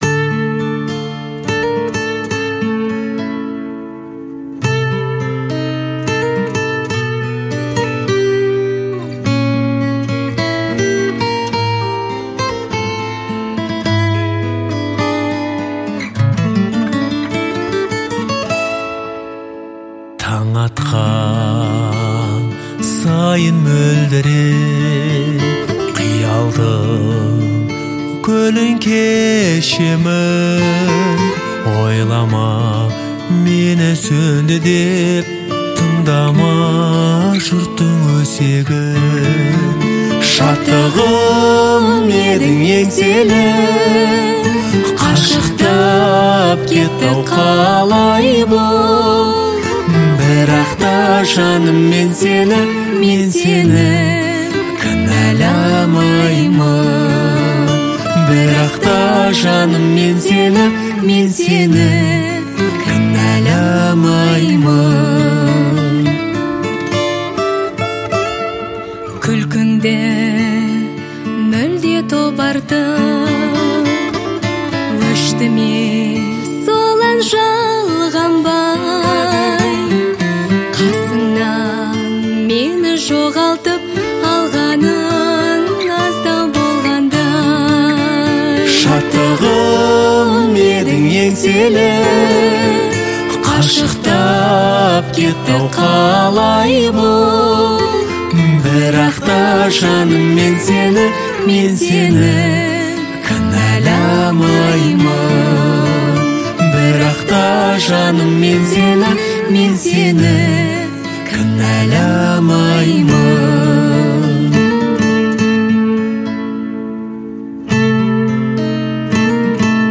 это трогательный дуэт в жанре поп